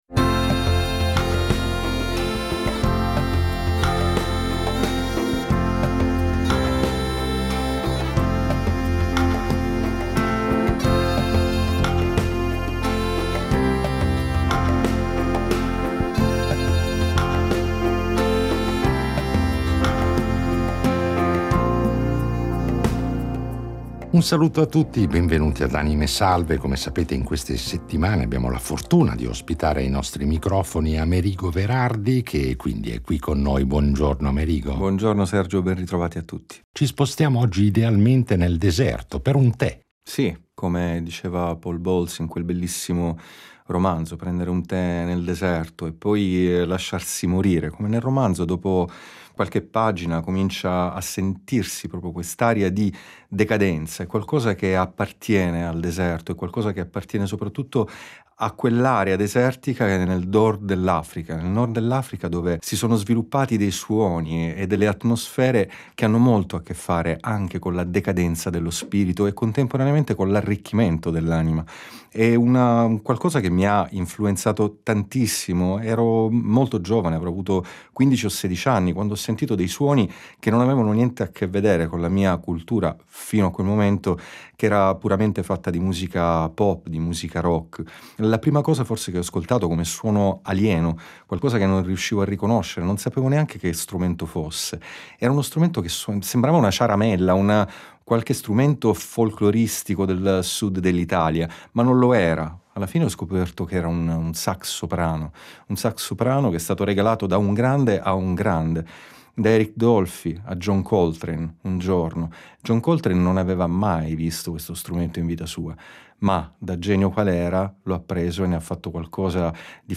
Un ospite prezioso che, come sempre ad “ Anime Salve ”, è venuto a trovarci con la sua chitarra per impreziosire i nostri itinerari sonori con interventi “live”.